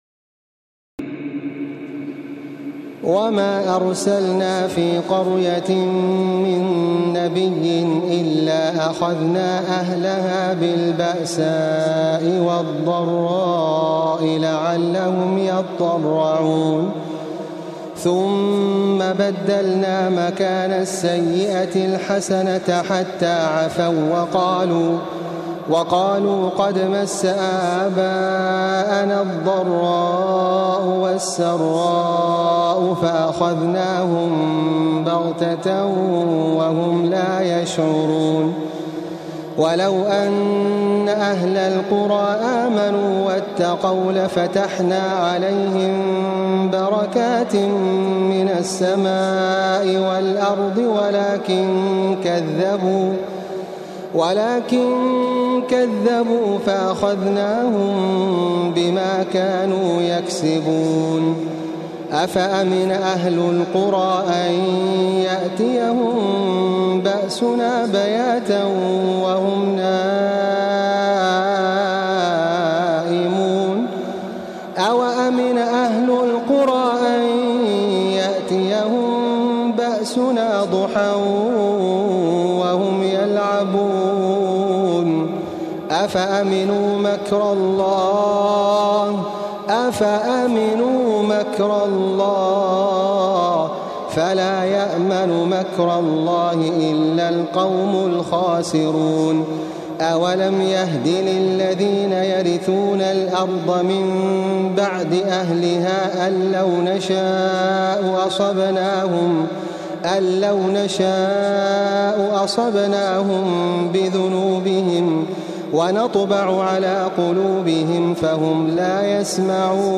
تراويح الليلة التاسعة رمضان 1435هـ من سورة الأعراف (94-171) Taraweeh 9 st night Ramadan 1435H from Surah Al-A’raf > تراويح الحرم المكي عام 1435 🕋 > التراويح - تلاوات الحرمين